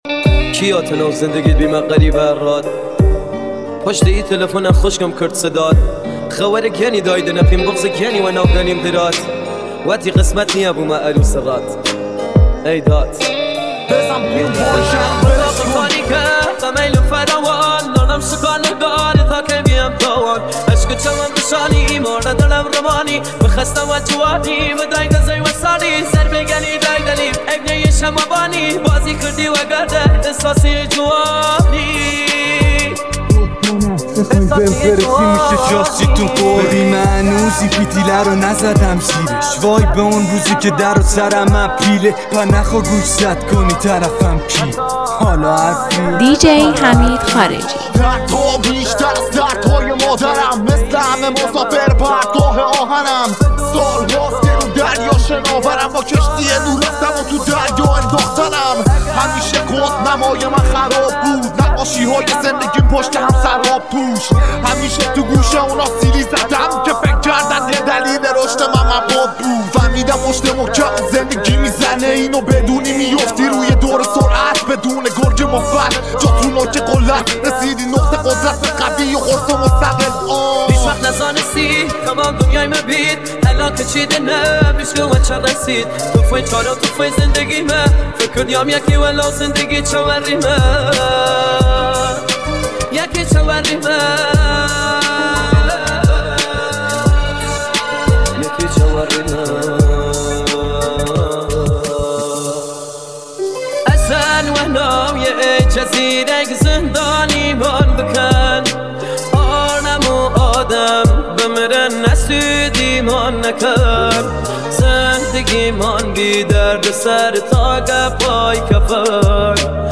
با ترکیبی بی‌نظیر از سبک کردی و رپ